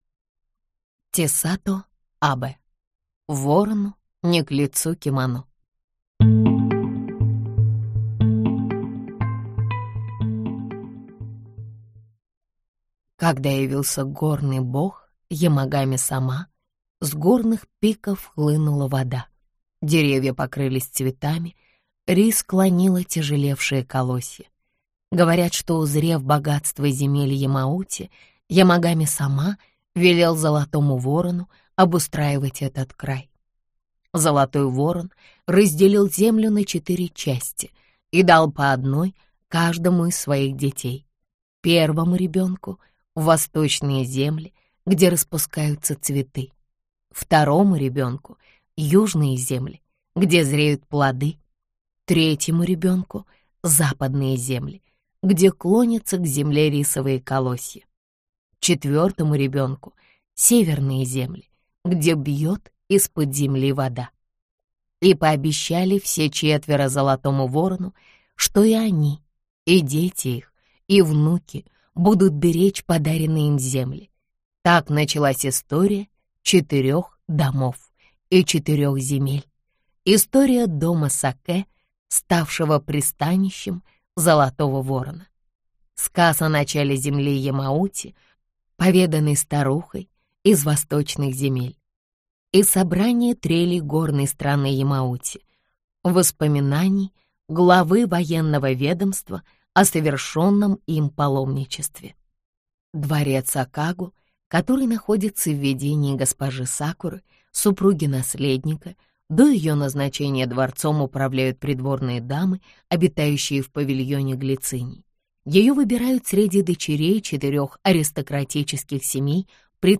Аудиокнига Ворону не к лицу кимоно | Библиотека аудиокниг